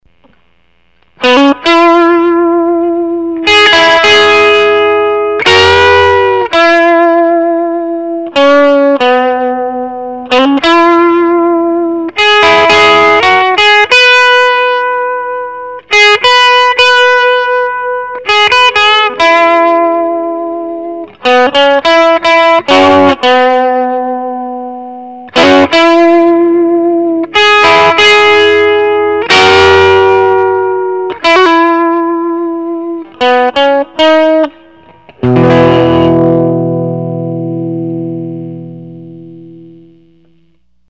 Amazing Grace, bridge single coil
Old Flame settings: ( volume: 3, raw: 3, bright: off, treble: 2, mid: 4, bass: 3, dwell: 6, mix: 3, tone: 4, master volume: 4)